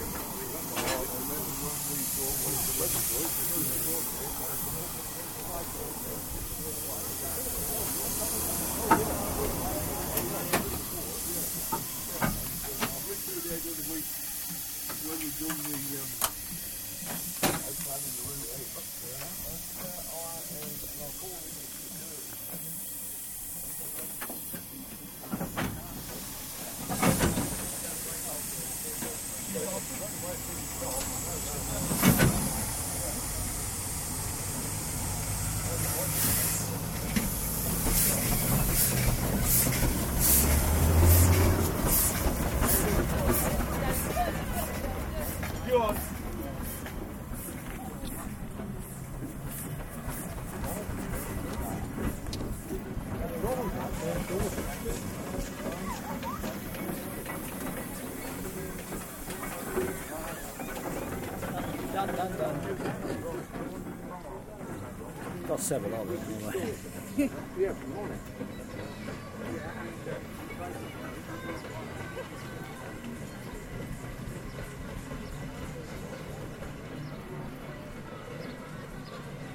Steam Engine
Steam Engine creaks, hisses and fires up at the village fete